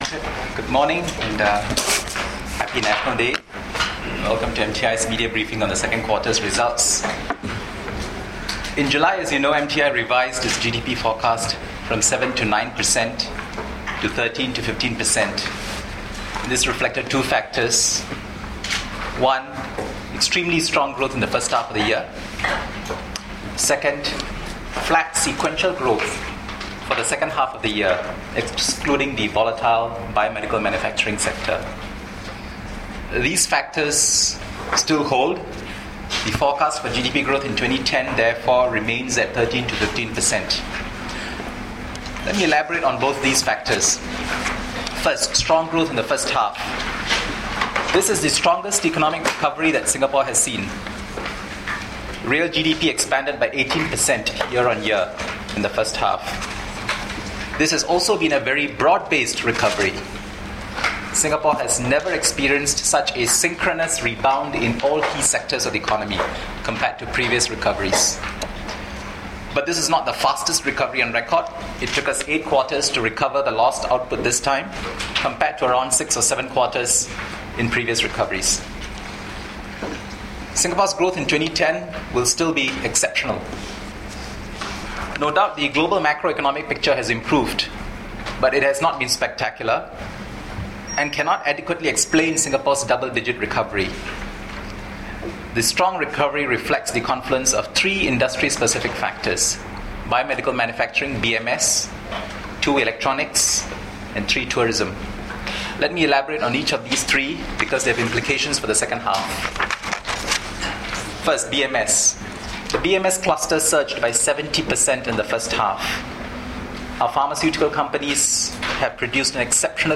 Opening Remarks by Mr Ravi Menon, Permanent Secretary, Ministry of Trade and Industry at the Economic Survey of Singapore (2Q10) Media Briefing, 10 August 2010